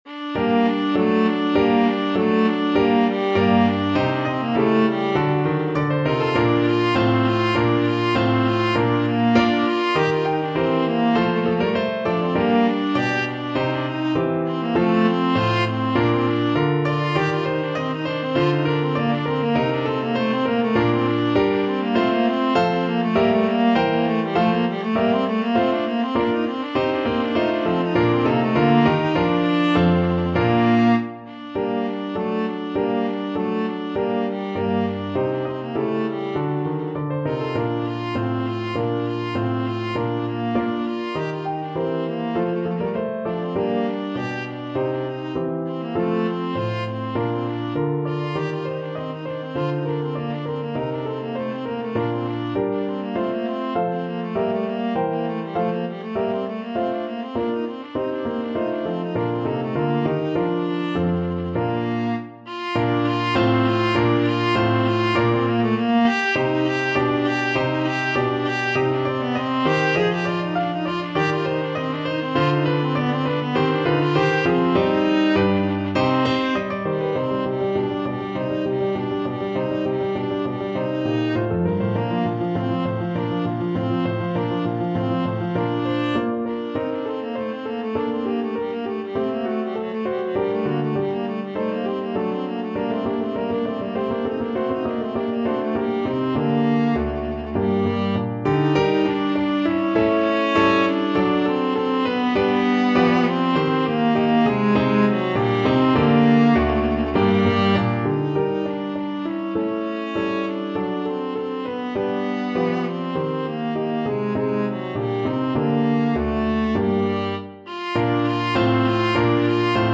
Viola
4/4 (View more 4/4 Music)
F4-Ab5
G minor (Sounding Pitch) (View more G minor Music for Viola )
II: Allegro (View more music marked Allegro)
Classical (View more Classical Viola Music)